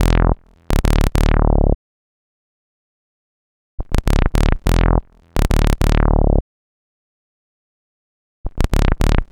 Bass 43.wav